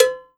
S_cowbell_2.wav